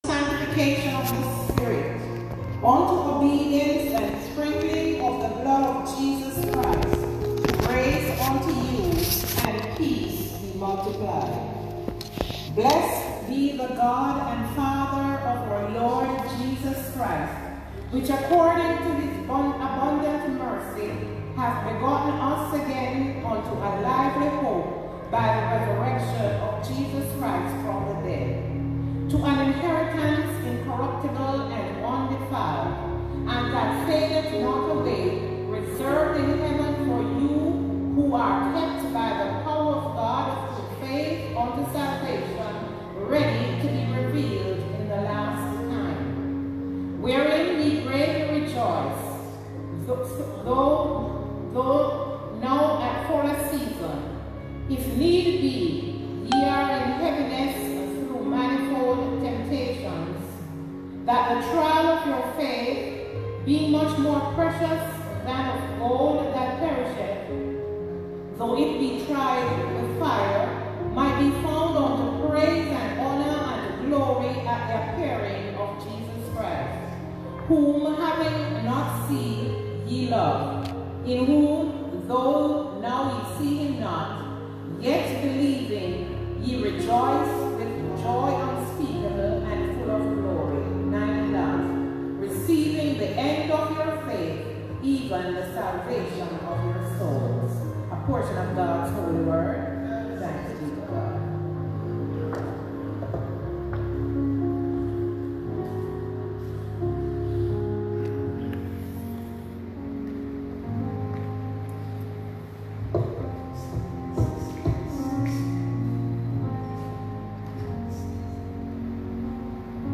Bethel Baptist Sermon 3/29
Bog Walk, St. Catherine, Jamaica
Sermon or written equivalent